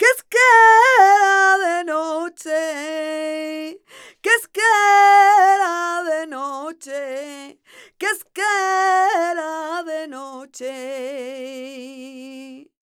46b21voc-fm.aif